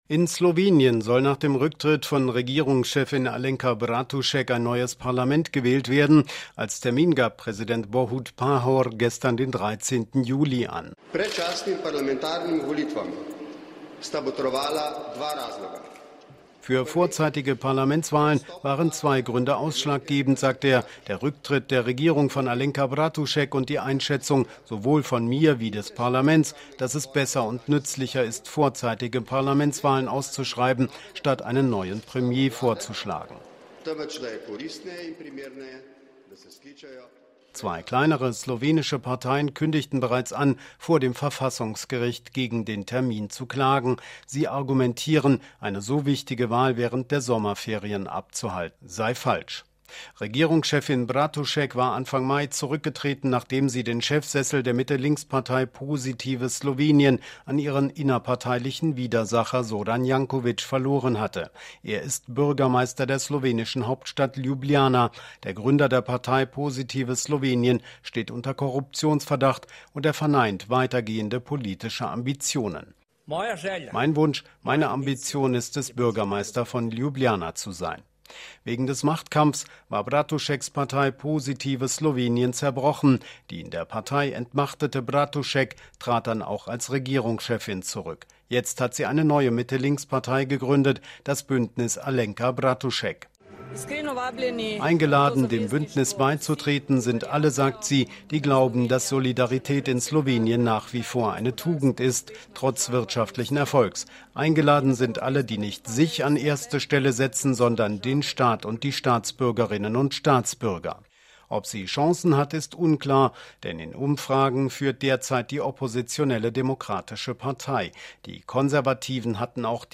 Ein Bericht zu den Neuwahlen in Slowenien